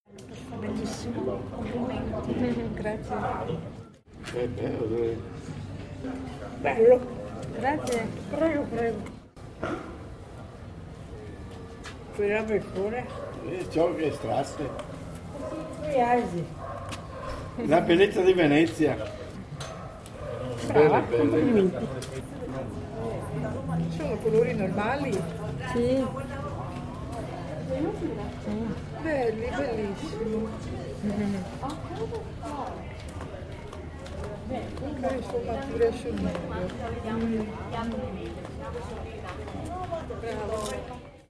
03/07/2016 16:30 Loin de la cohue écumant la place Saint-Marc, ce quartier est vivant, populaire et coloré.
Les habitants viennent me saluer, regardent les dessins, me gratifient d’un “complimento” musical et repartent le sourire au lèvres.